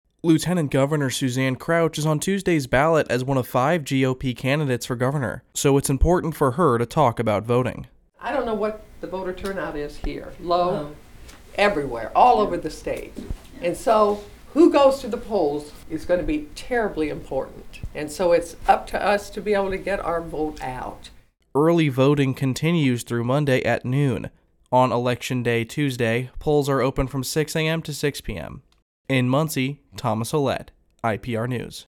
Suzanne Crouch gives a speech in front of Muncie Republicans on May 3, 2024.